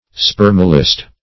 spermalist - definition of spermalist - synonyms, pronunciation, spelling from Free Dictionary Search Result for " spermalist" : The Collaborative International Dictionary of English v.0.48: Spermalist \Sper"mal*ist\, n. (Biol.)